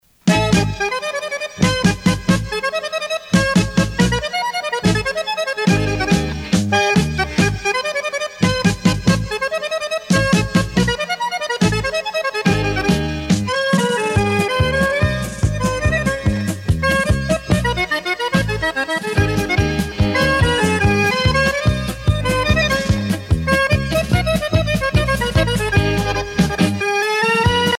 paso musette
Tempo Grande vitesse
Pièce musicale éditée